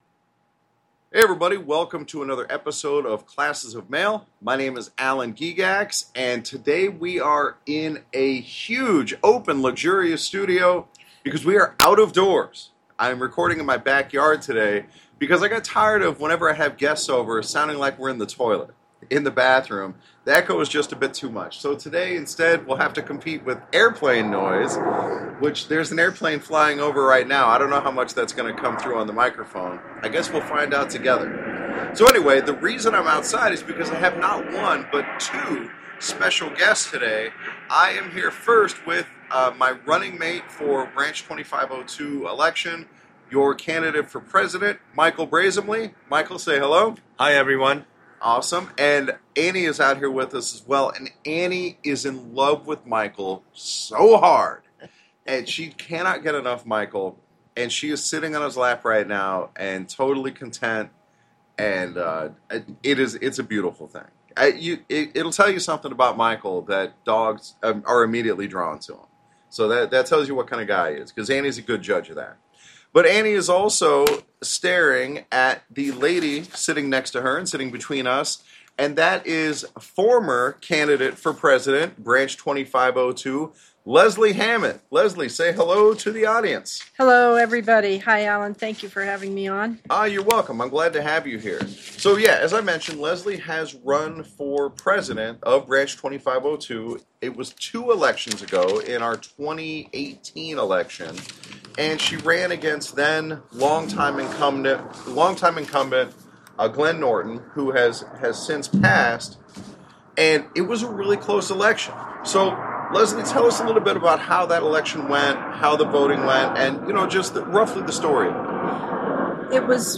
This episode was recorded in my back yard, so there's no echo.